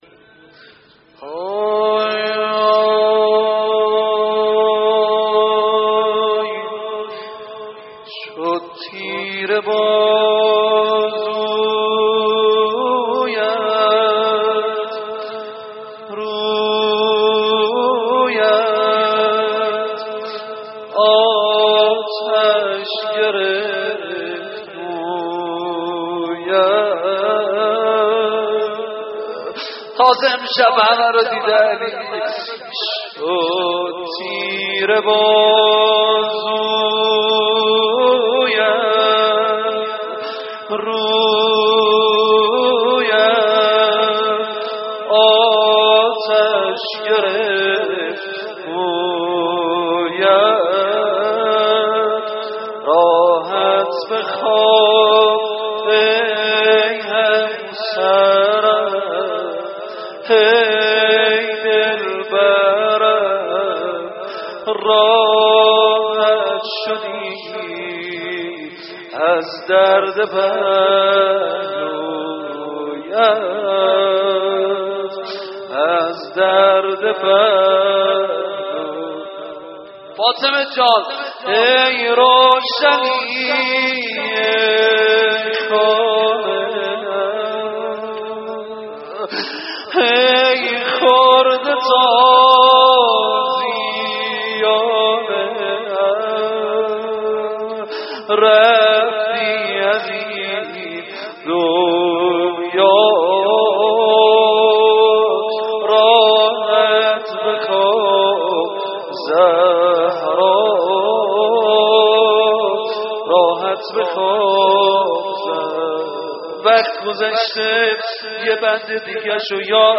مداحی
فاطمیه 94 روضه